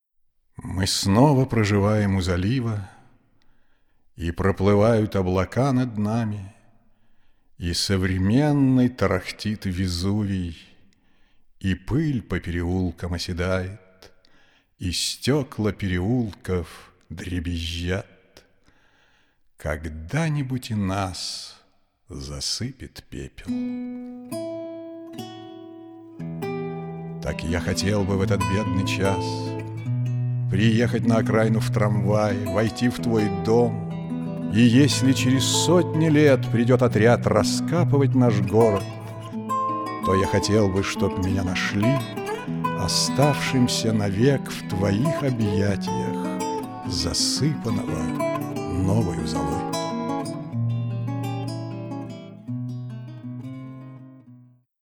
Warmer samtiger Bariton
Sprechprobe: Sonstiges (Muttersprache):